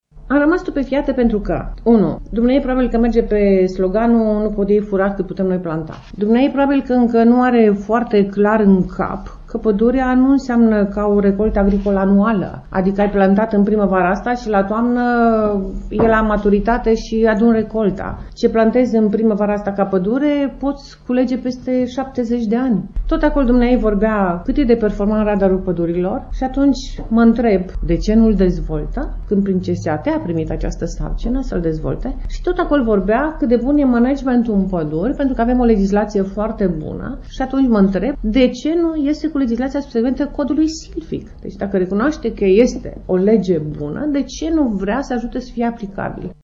Doina Pană a declarat astăzi, într-o conferinţă de presă că a fost “stupefiată” când a auzit-o pe actuala ministră a mediului spunând că fenomenul tăierii pădurilor nu este atât de grav în România şi a acuzat-o pe Cristina Paşca Palmer că nu face nimic nici pentru a aplica noul Cod Silvic.